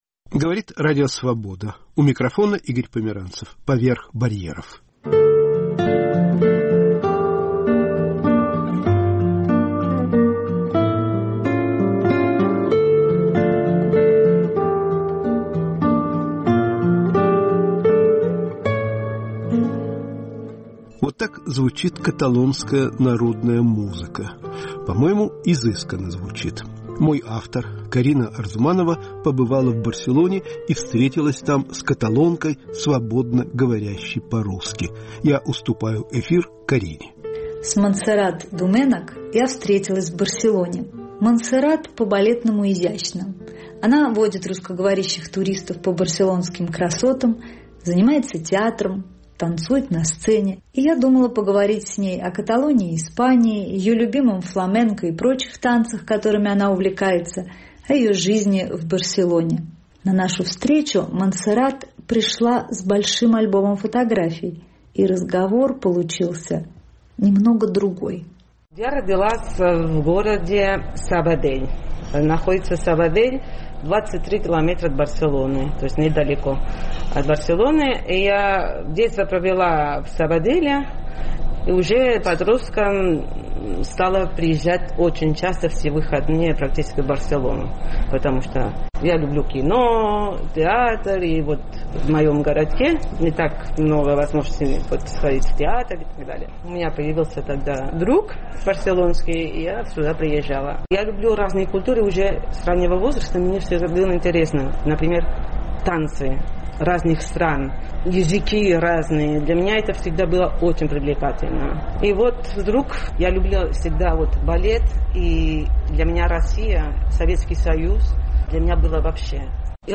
О любви к Каталонии, России и балету.*** Болезнь и творчество. В передаче участвуют писатели, литературовед и психолог.